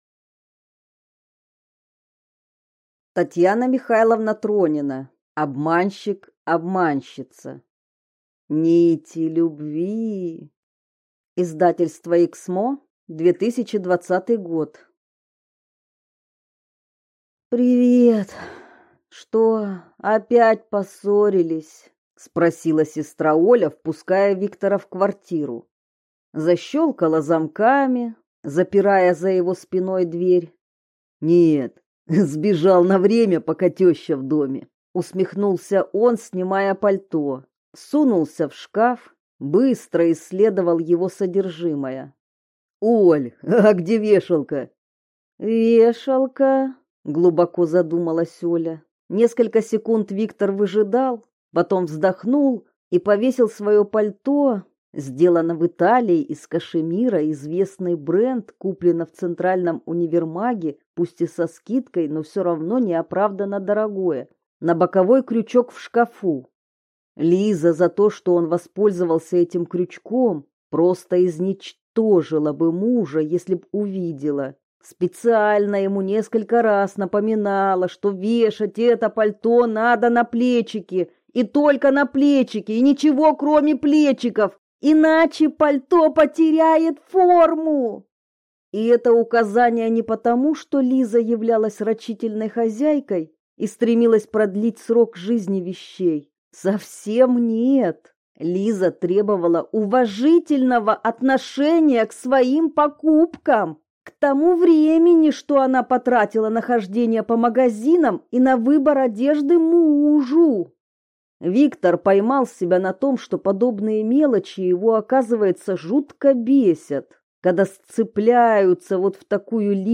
Аудиокнига Обманщик, обманщица | Библиотека аудиокниг